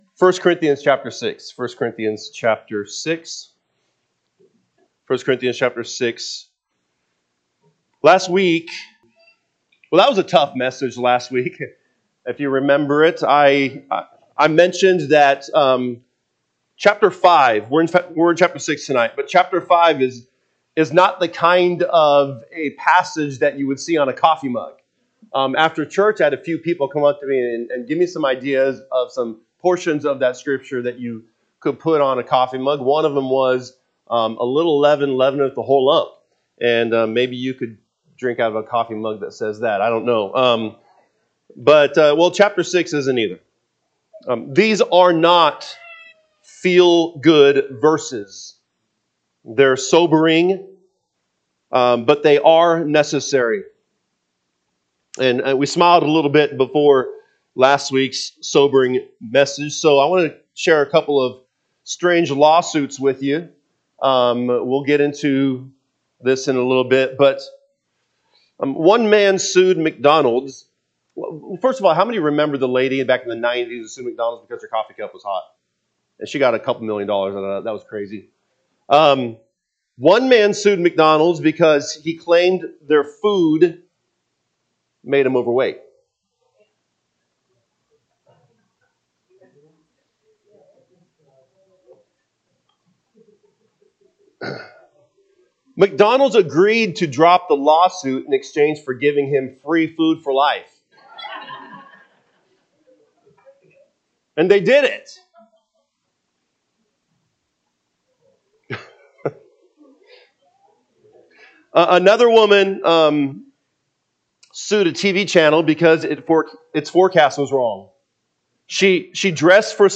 November 23, 2025 pm Service 1 Corinthians 6:1-8 (KJB) 6 Dare any of you, having a matter against another, go to law before the unjust, and not before the saints? 2 Do ye not know that th…
Sunday PM Message